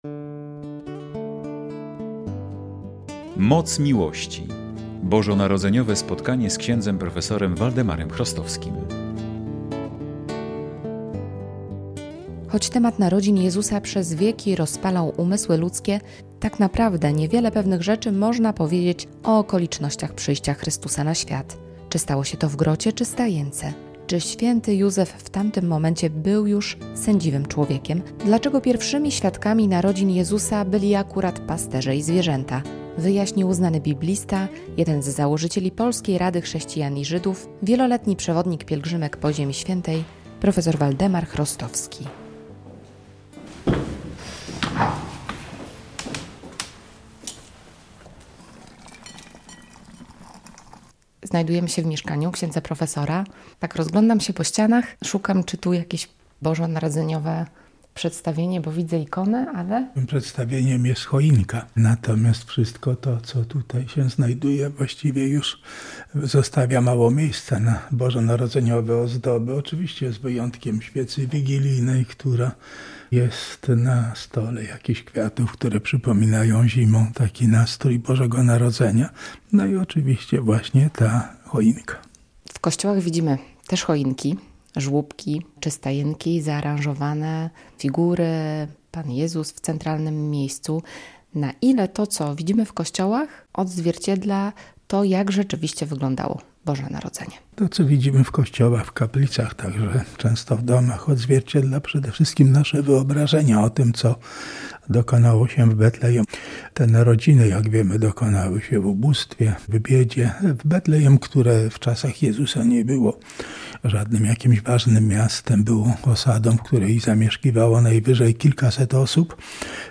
Radio Białystok | Reportaż | "Moc miłości.